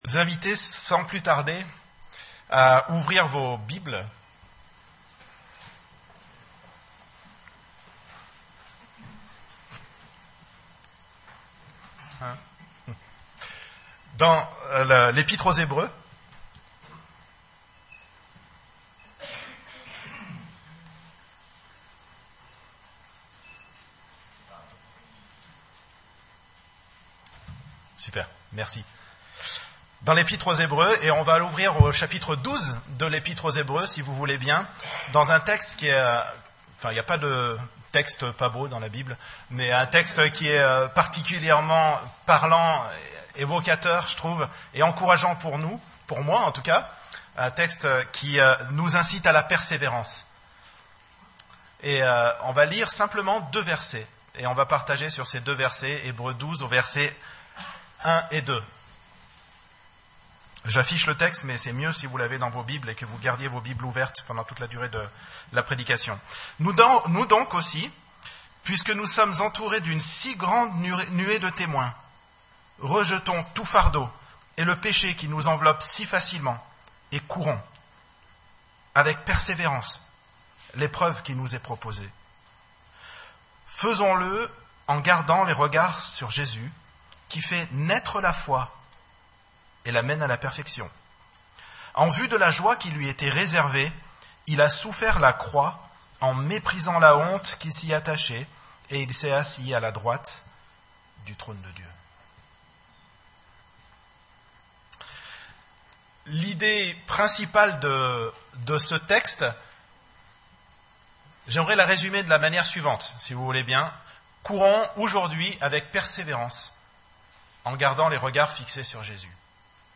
Type De Service: Culte Dimanche